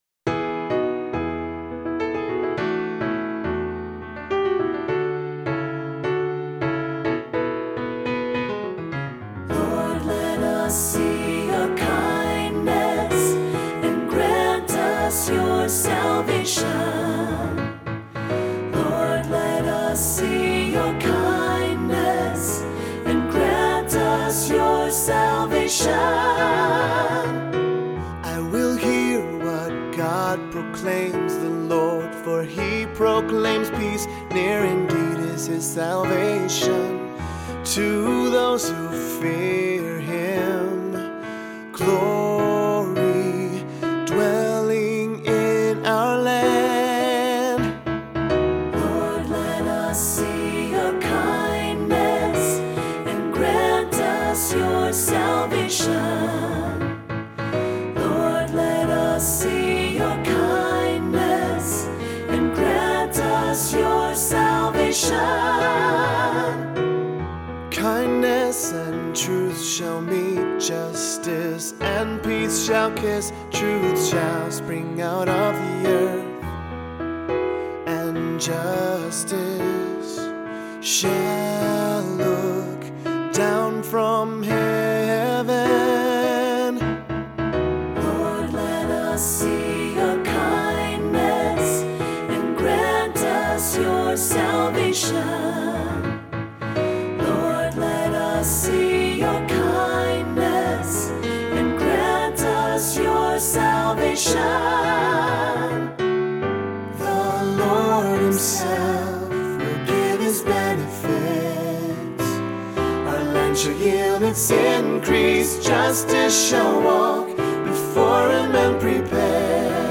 Voicing: Three-Part equal; Cantor; Assembly